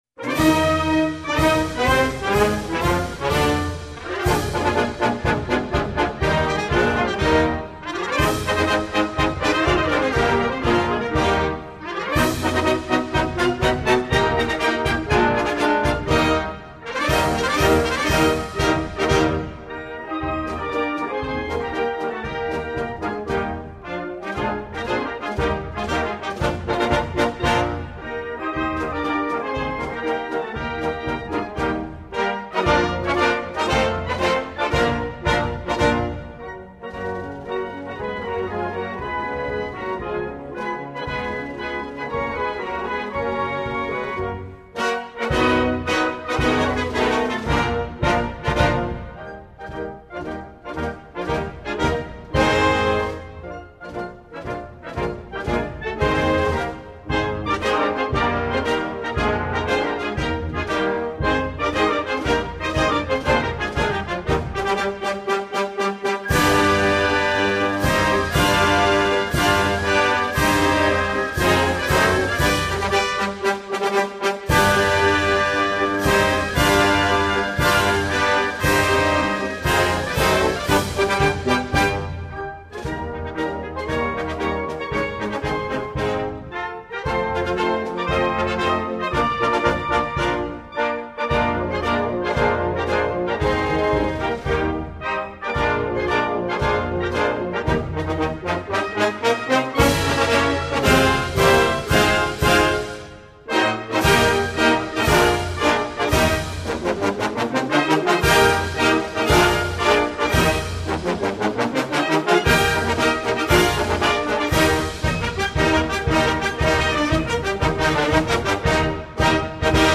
United_States_Navy_Band_-_National_Anthem_of_Chile.mp3